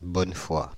Bonnefoi (French pronunciation: [bɔnfwa]
Fr-Bonnefoi.ogg.mp3